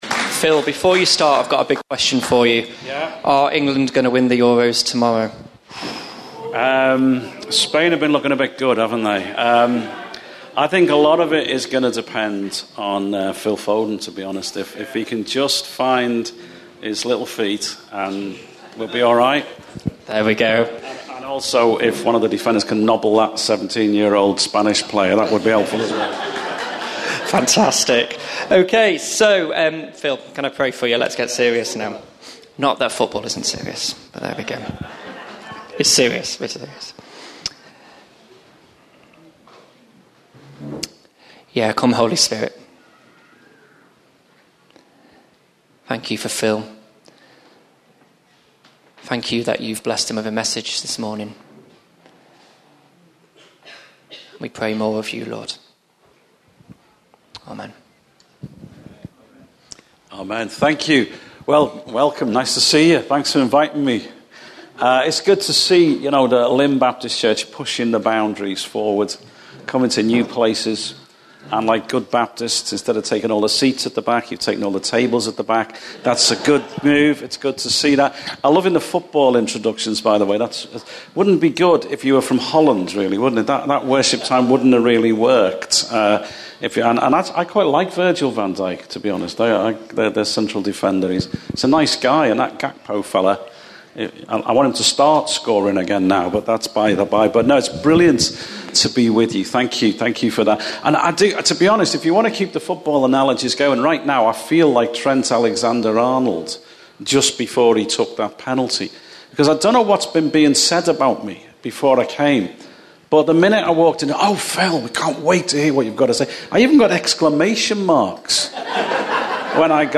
Church Away Day 2024 , Live stream Passage: Psalm 137 Service Type